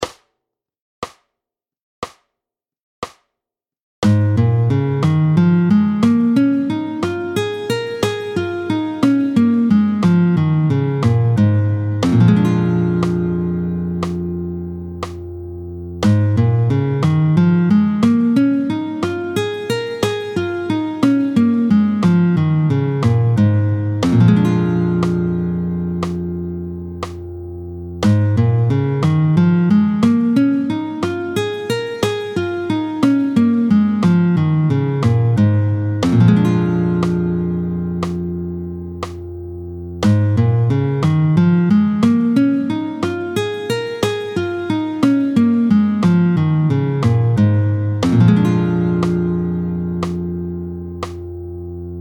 31-05 Doigté 5 en Sib, tempo 60